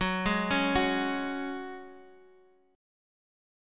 Gbsus2 chord